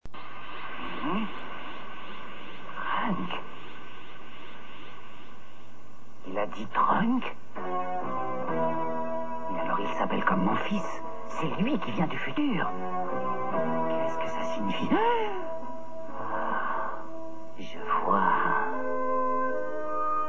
Les voix des personnages